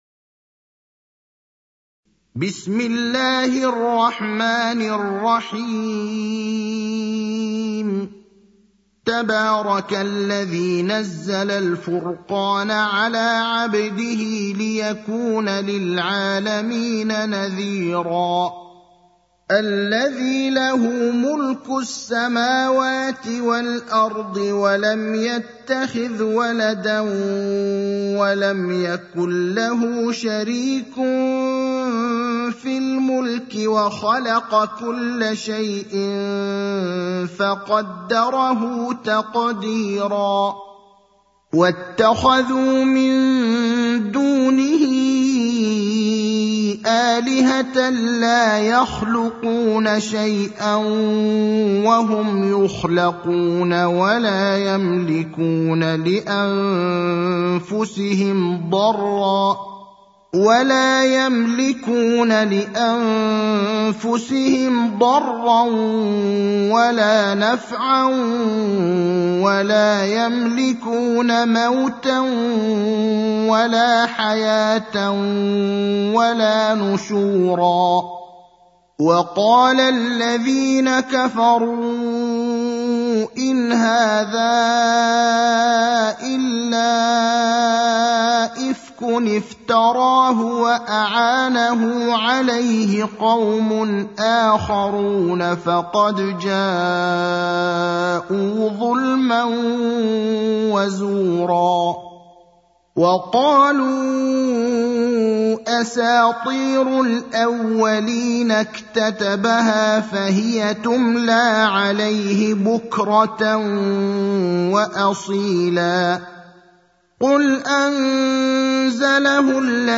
المكان: المسجد النبوي الشيخ: فضيلة الشيخ إبراهيم الأخضر فضيلة الشيخ إبراهيم الأخضر سورة الفرقان